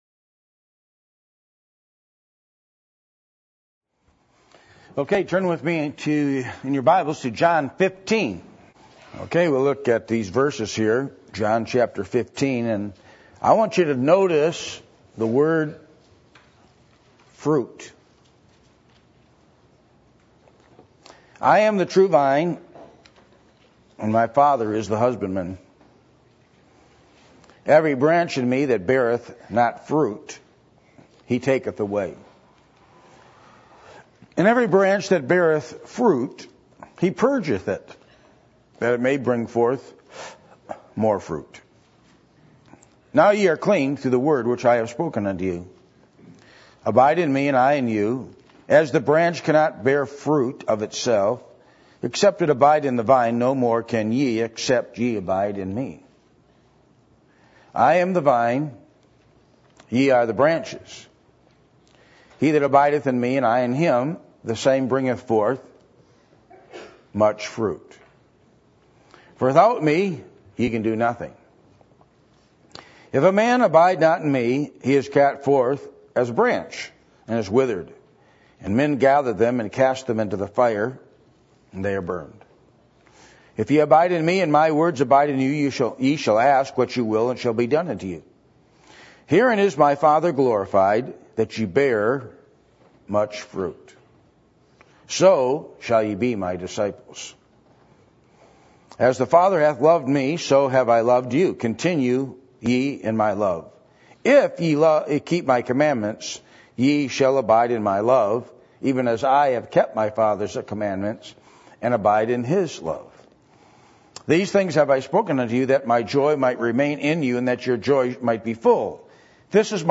Passage: John 15:1-6 Service Type: Midweek Meeting %todo_render% « The Scripture Truth About Separation What Kind Of Person Are You?